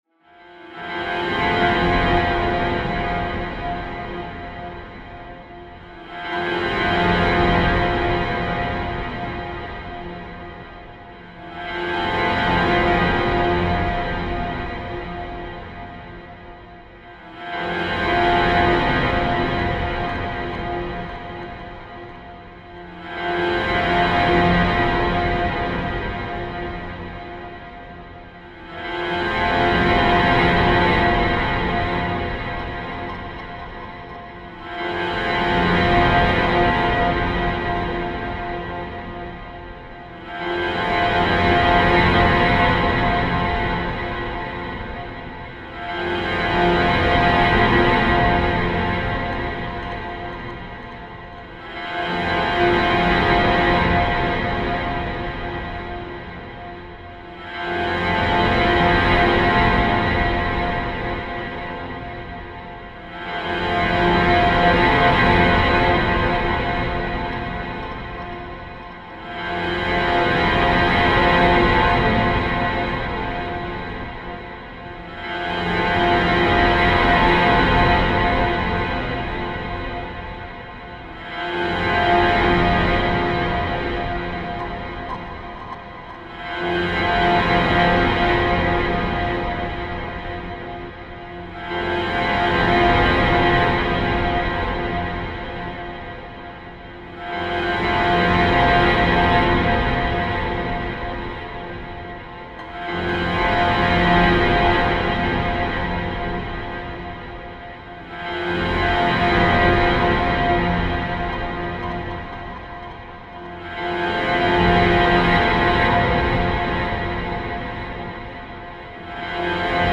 free horror ambience 2